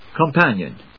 音節com・pan・ion 発音記号・読み方
/kəmpˈænjən(米国英語)/